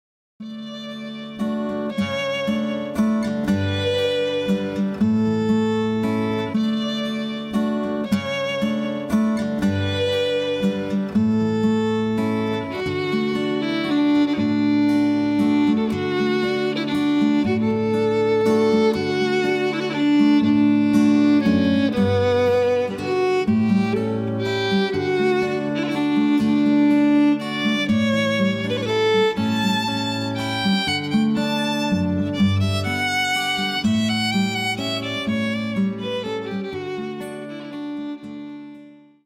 a collection of danceable waltzes.